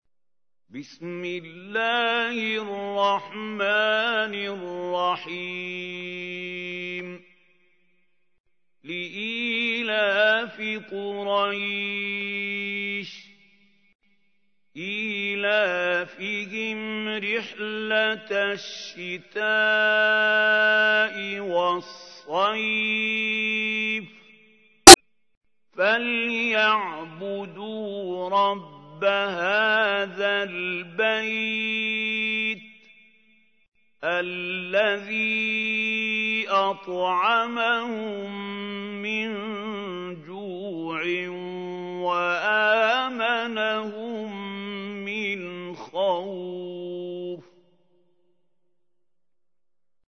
تحميل : 106. سورة قريش / القارئ محمود خليل الحصري / القرآن الكريم / موقع يا حسين